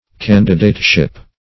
Candidateship \Can"di*date*ship\, n.